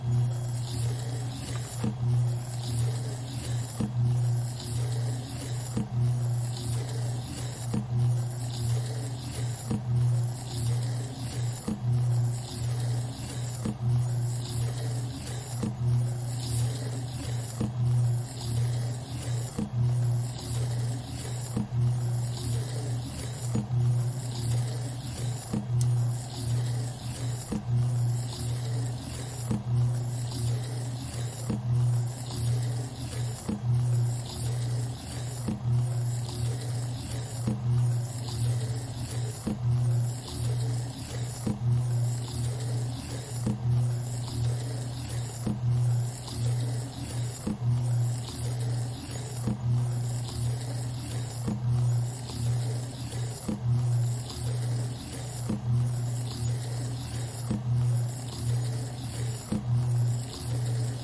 盒式磁带 "卷对卷磁带机单卷转动Cu的机械砰声
Tag: 卷轴 机械 磁带 单一的 机器 重击声 车削